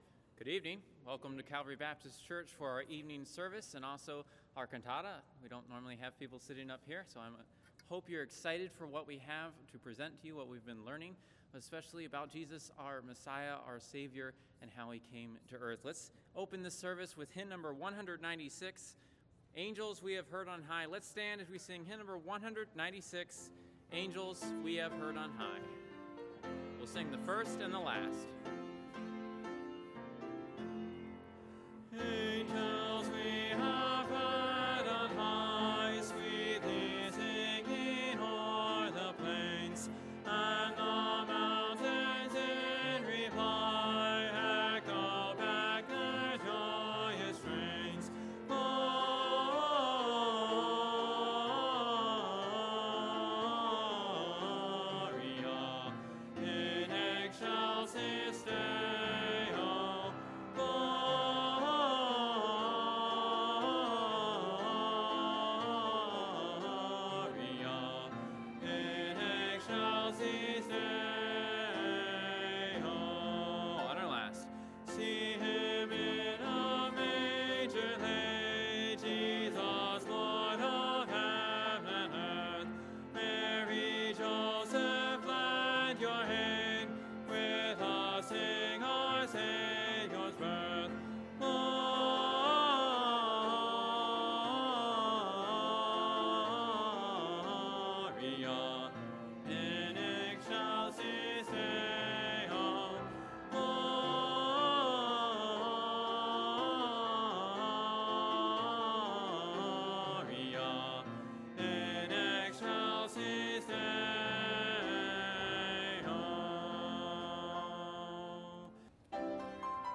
Christmas Choir Cantata 2018 – Calvary Baptist Church
Christmas Choir Cantata 2018
Christmas-Cantata-Music-2018.mp3